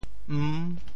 唔 部首拼音 部首 口 总笔划 10 部外笔划 7 普通话 wú ńg ń 潮州发音 潮州 m6 文 潮阳 m6 文 澄海 m6 文 揭阳 m6 文 饶平 m6 文 汕头 m6 文 中文解释 潮州 m6 文 ①象声词。